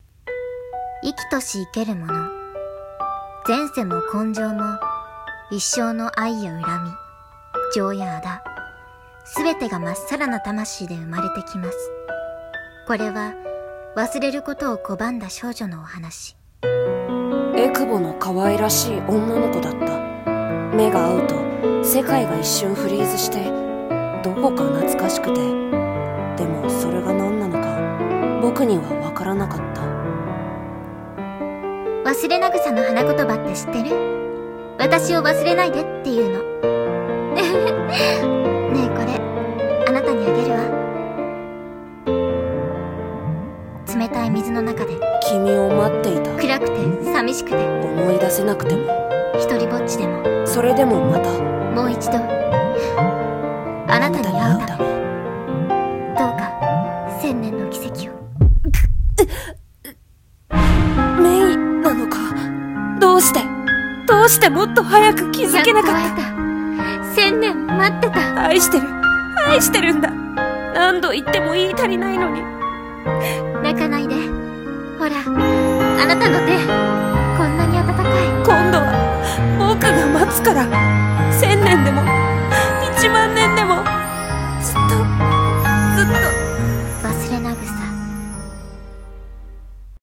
CM風声劇「勿忘草」